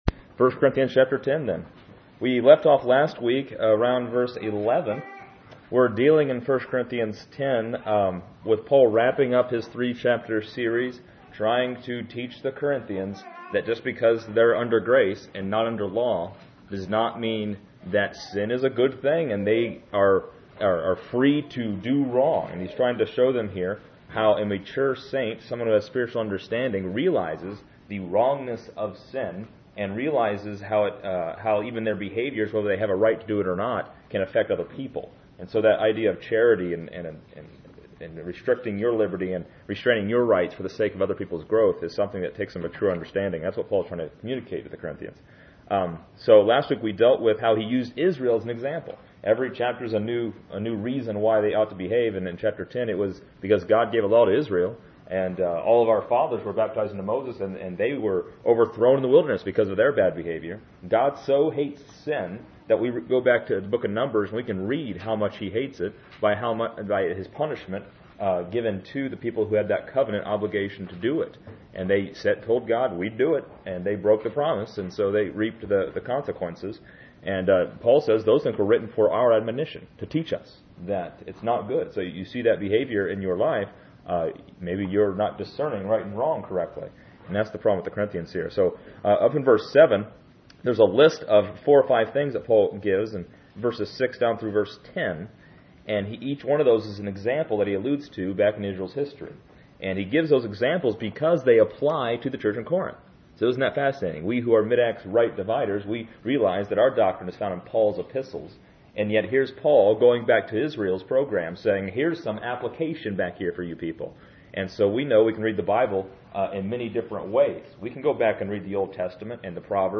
This lesson is part 26 in a verse by verse study through 1 Corinthians titled: Take Heed and Flee.